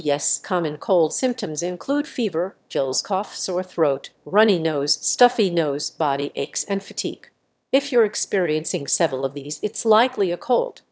illness1_AirConditioner_2.wav